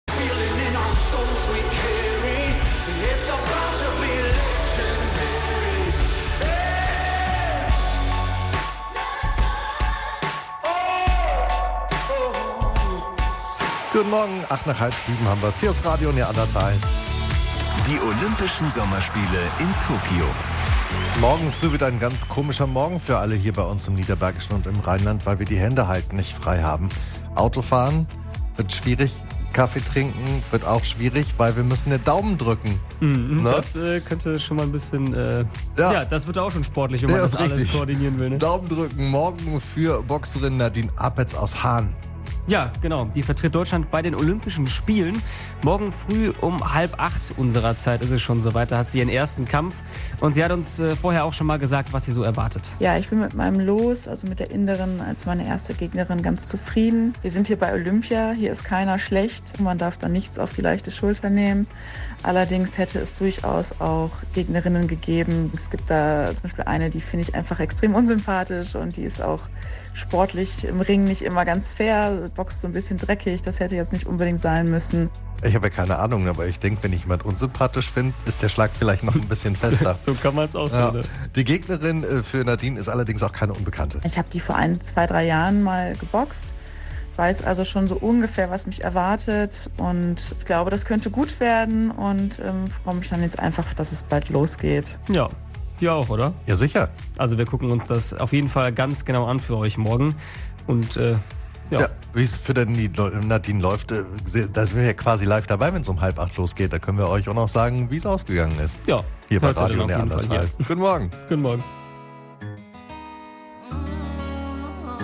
Olympiade in Tokio - da ist Daumen-Drücken angesagt, denn Nadine tritt am Dienstagmorgen ihren ersten Kampf bei der Olympiade in Tokio gegen eine Inderin an. In einem Telefon-Interview hat sie uns erzählt, was auf sie zukommt und womit sie rechnet.